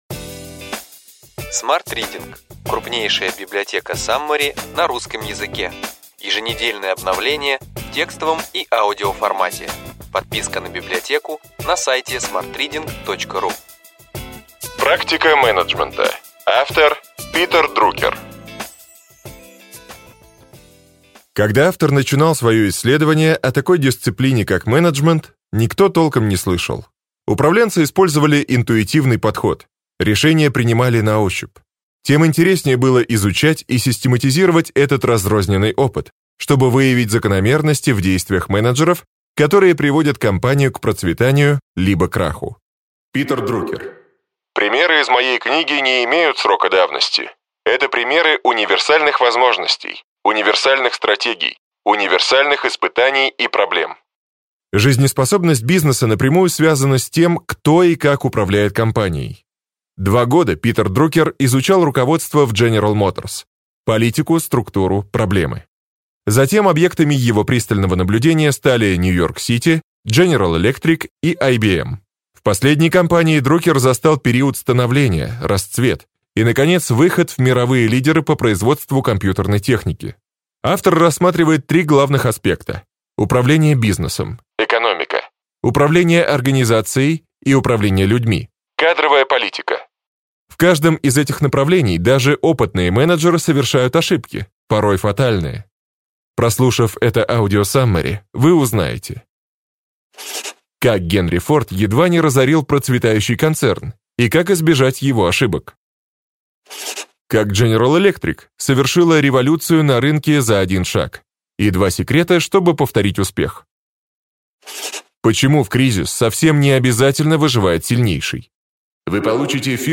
Аудиокнига Ключевые идеи книги: Практика менеджмента.